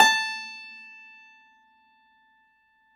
53f-pno17-A3.aif